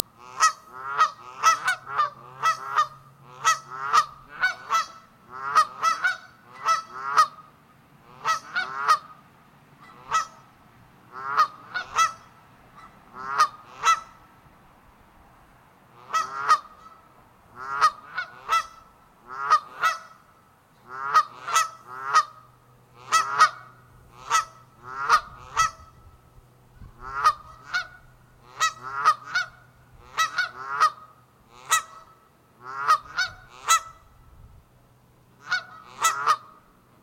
Canada Goose – River Island Conservancy
Audio Call
Flocks of geese are often vocal and communicate with each other during flight.
Branta_canadensis_-_Canada_Goose_-_XC62259.ogg